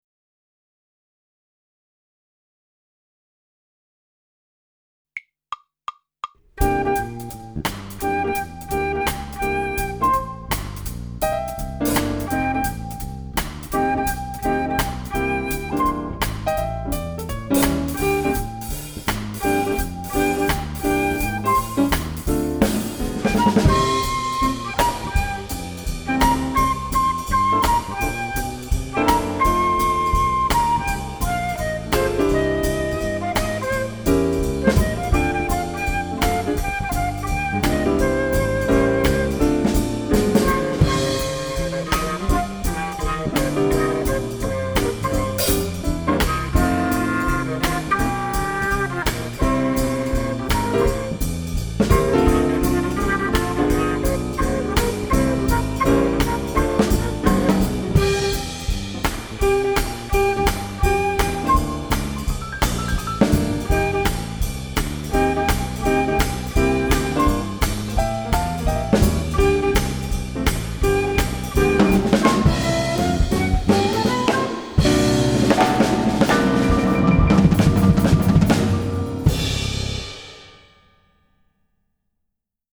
Besetzung: Instrumentalnoten für Flöte